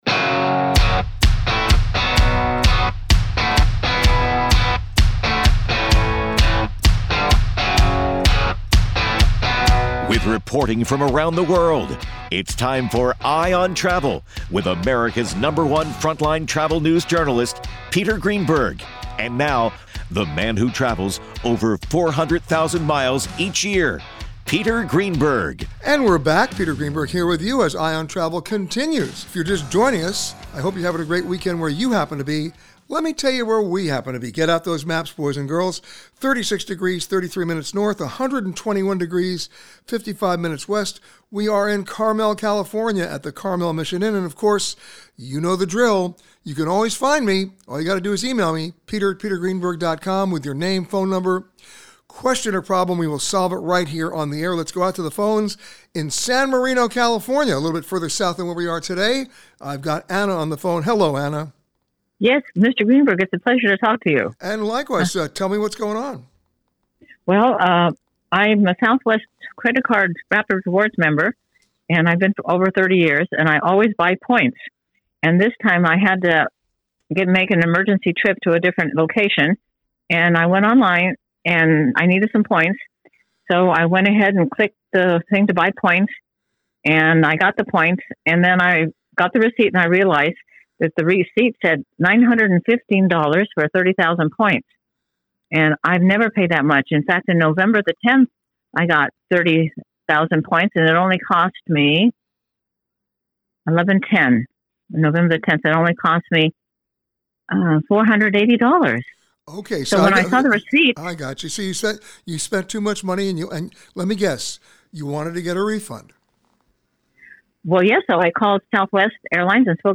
This week, Peter answered your questions from Carmel Mission Inn in Carmel, California.
Peter answers your questions on buying airline points, how far in advance to book domestic airline tickets, and more. Each week during our CBS Radio Show, Eye on Travel, Peter answers your travel questions.